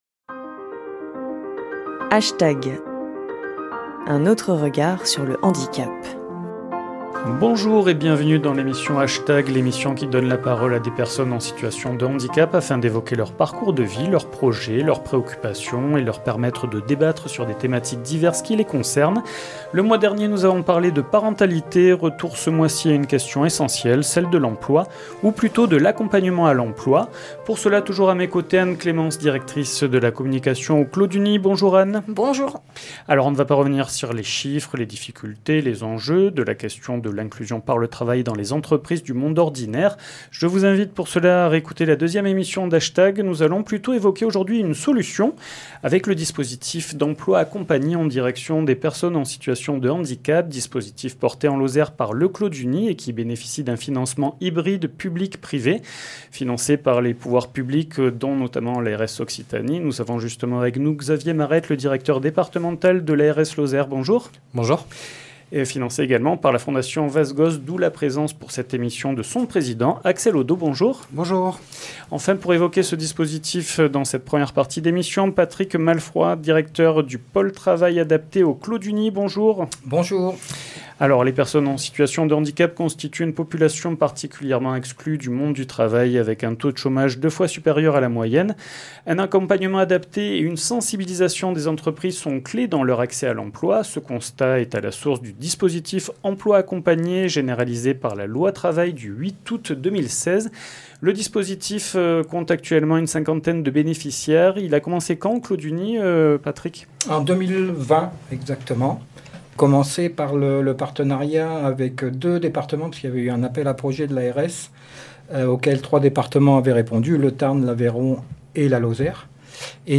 Une émission animée